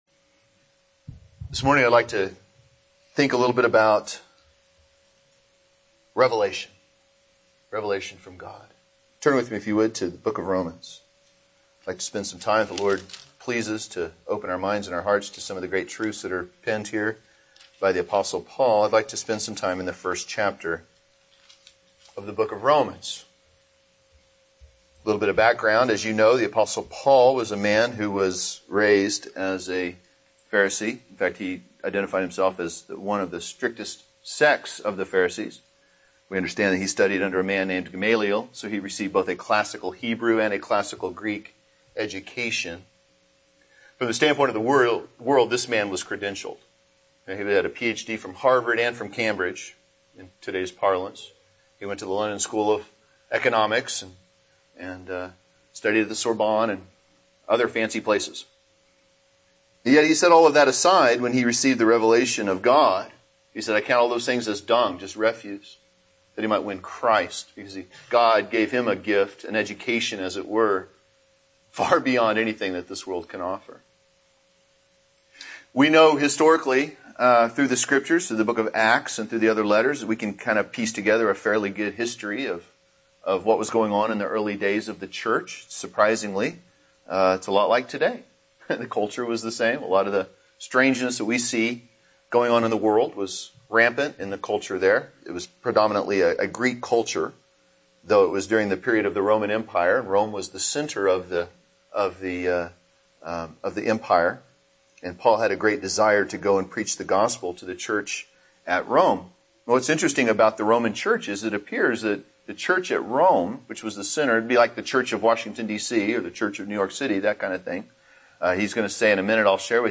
Bethlehem Primitive Baptist Church of Oklahoma City